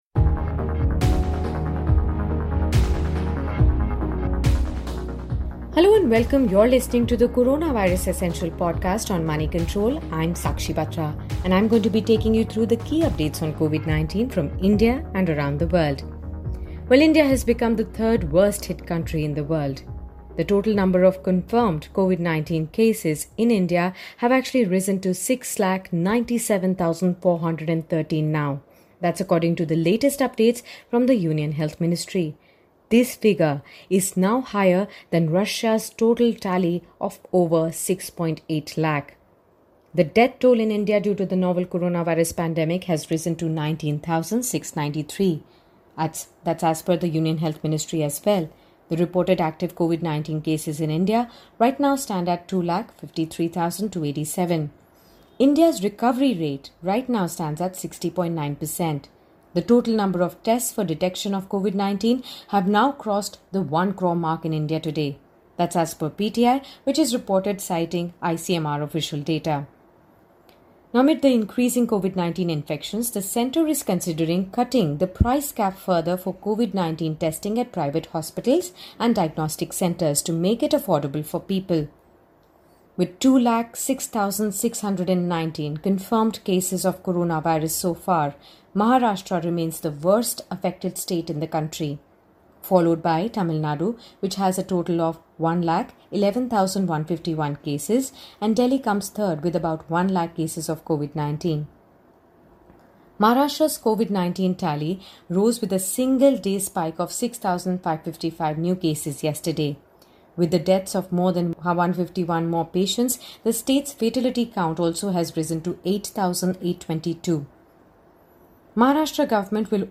she gives all the top news on the COVID-19 pandemic.